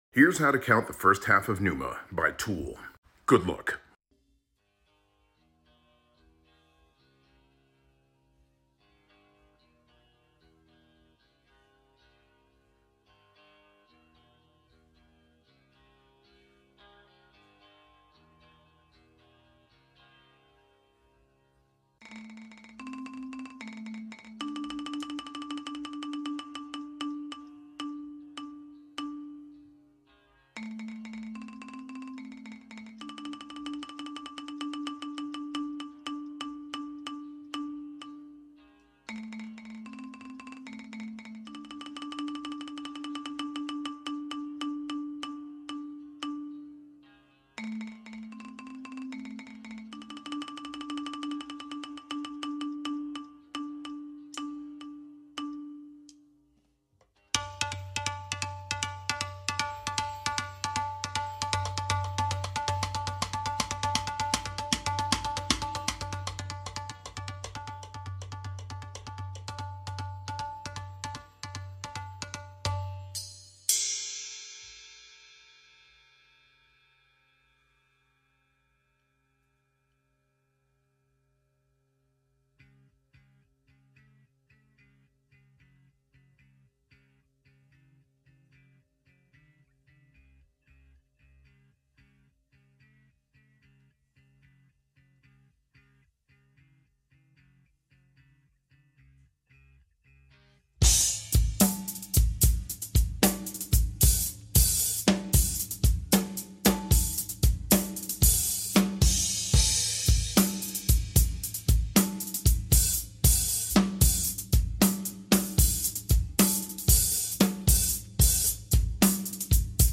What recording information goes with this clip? Forgive the stick clicks!